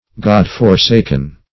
godforsaken \god"for*sak`en\, god-forsaken \god"-for*sak`en\adj.